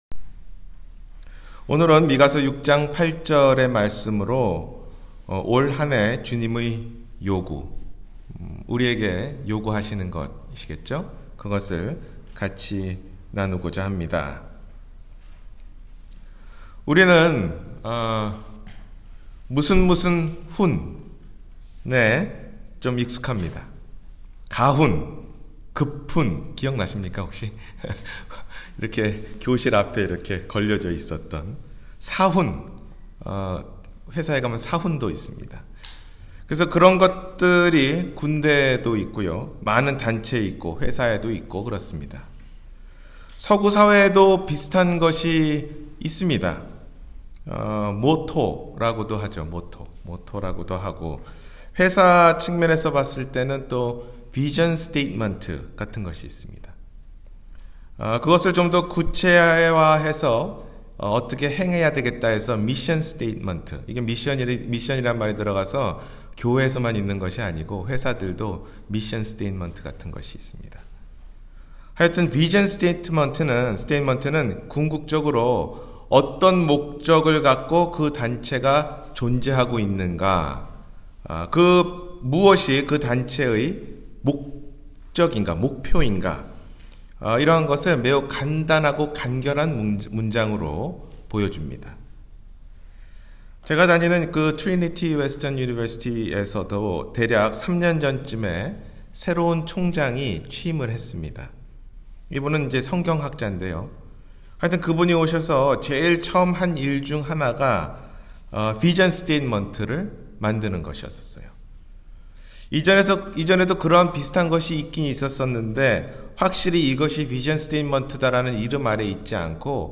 올 한해 주님의 요구 – 주일설교